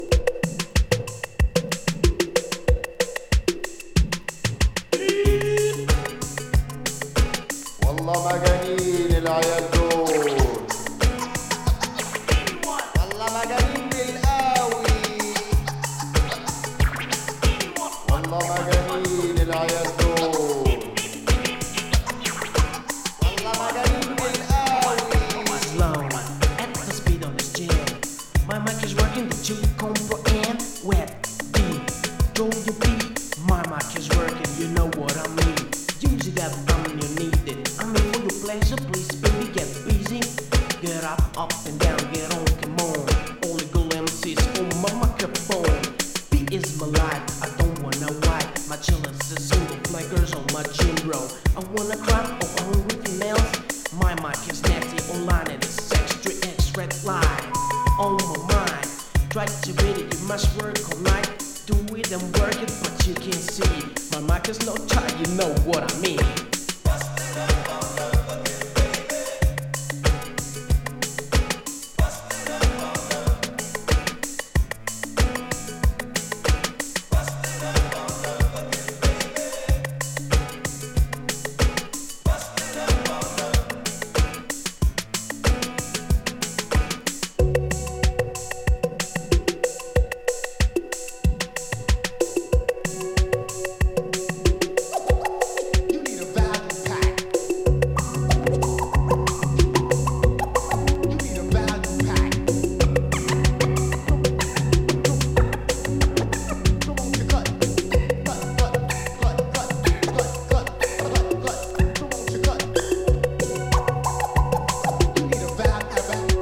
'90年リリースの割にイナタ度数高めのエレクトロ〜Ground Beat〜味わい深いCUt-Up等収録。